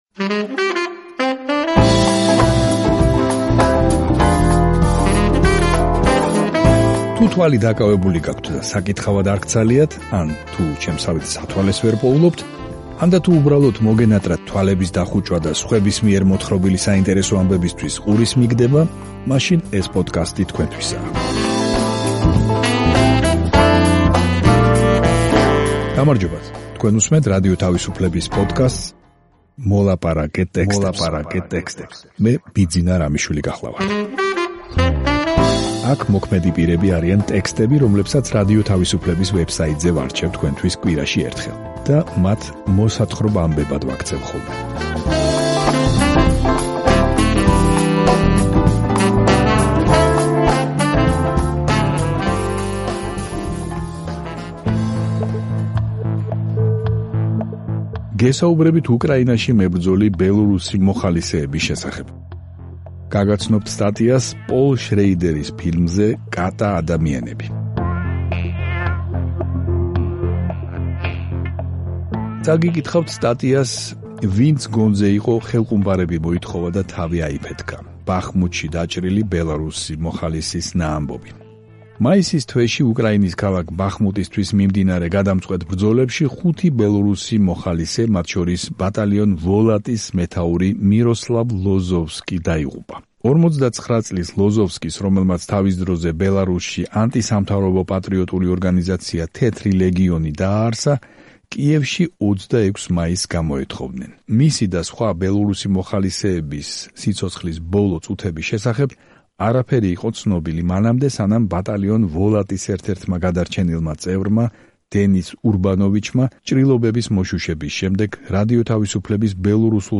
თუ თვალი დაკავებული გაქვთ და საკითხავად არ გცალიათ, ან თუ სათვალეს ვერ პოულობთ, ანდა, თუ უბრალოდ მოგენატრათ თვალების დახუჭვა და სხვების მიერ მოთხრობილი საინტერესო ამბებისთვის ყურის მიგდება, მაშინ ეს პოდკასტი თქვენთვისაა.